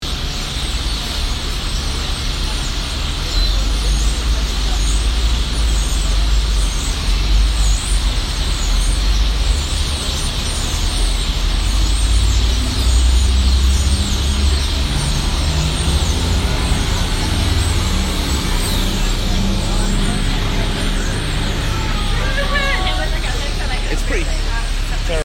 Birds at dusk, Auckland